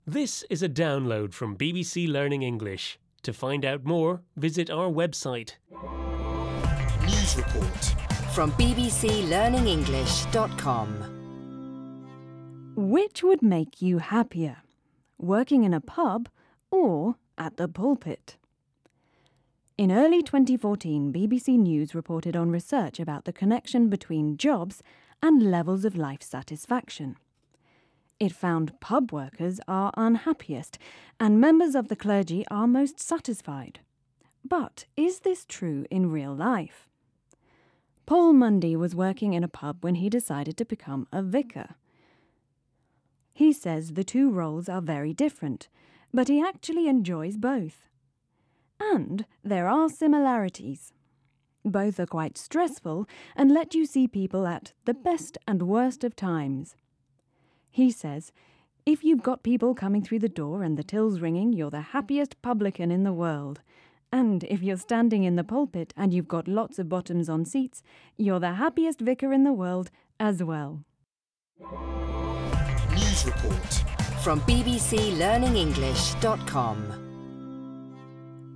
unit-7-3-1-u7_s4_news_report_download.mp3